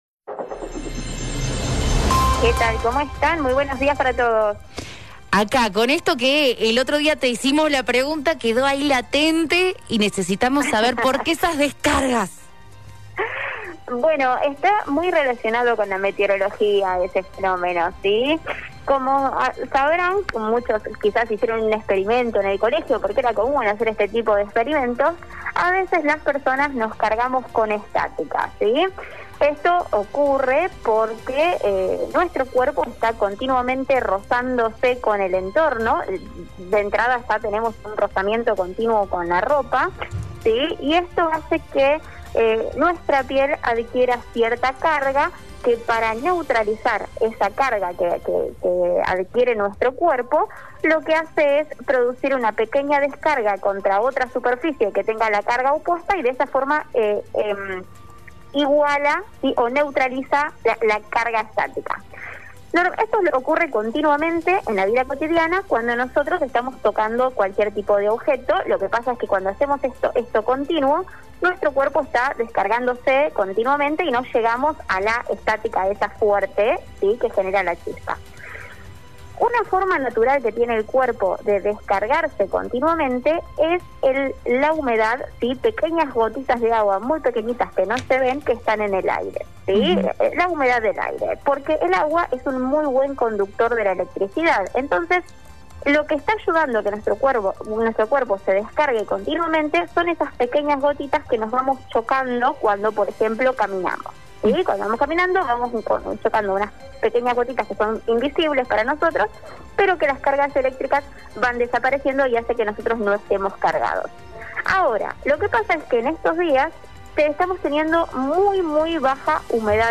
“Esto pasa cotidianamente de manera cotidiana sin ser consiente” agregaba la meteoróloga.